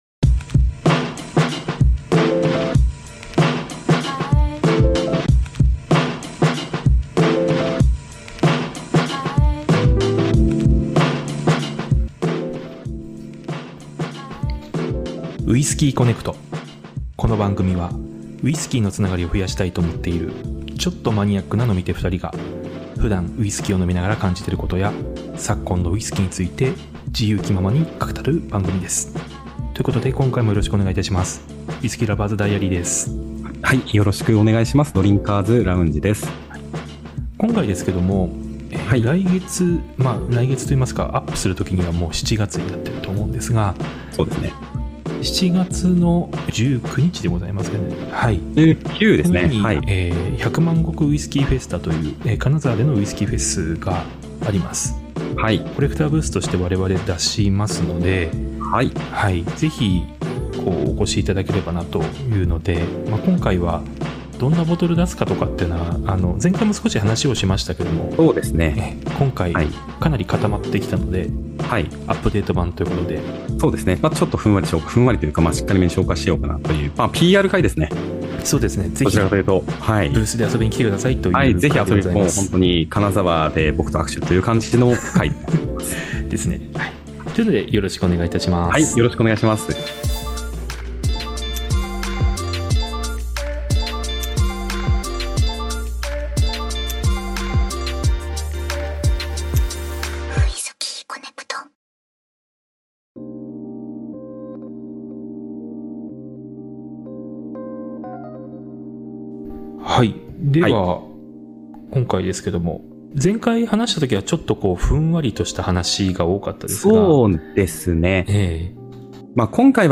首都圏と地方都市、それぞれのテイスティング視点を持つ、ちょっとマニアックな飲み手がお届けするポッドキャスト「ウイスキーコネクト」。
Audio Channels: 2 (stereo)